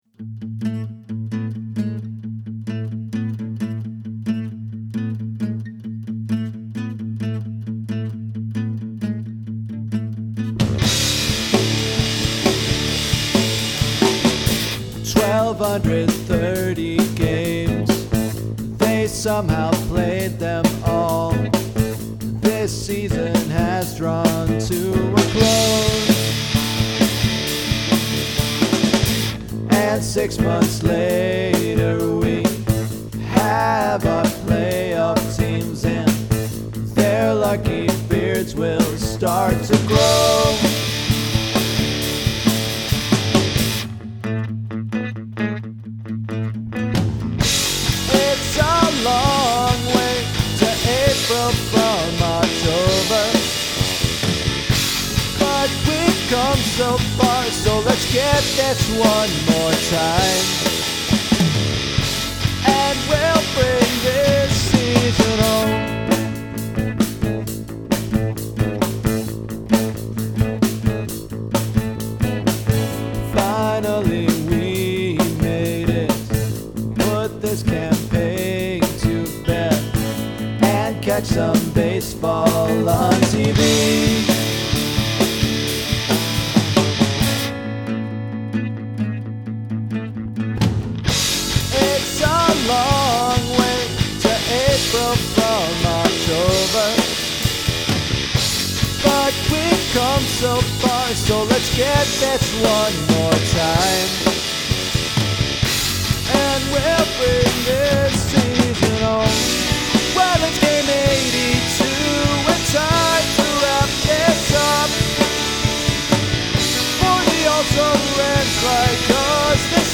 So, how’s about a fun little pop-rock tune to commemorate the occasion?
Like the last tune, this is a bit of an homage to Coheed and Cambria – their most recent album closes with a simple and fun rock song, which is what I tried to do here.